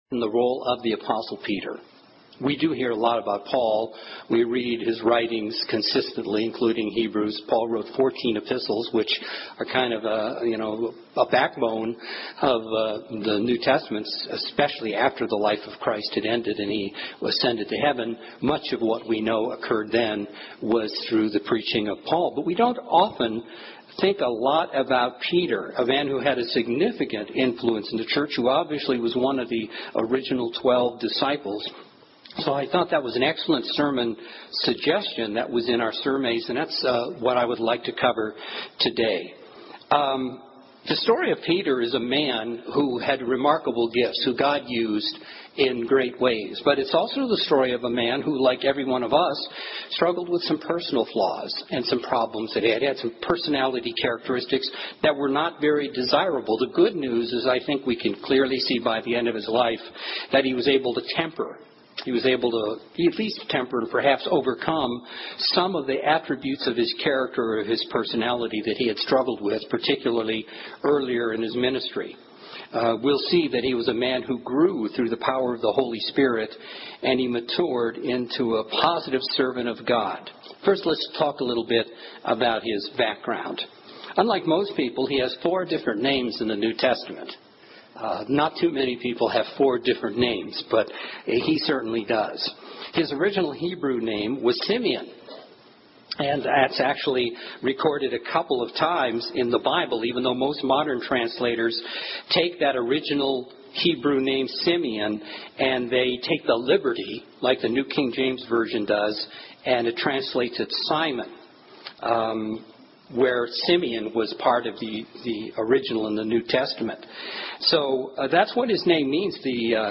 Peter had remarkable gifts that God used in remarkable ways, and he had some aspects of his character that he struggled with in his life. Learn more about his successes and failures in this sermon.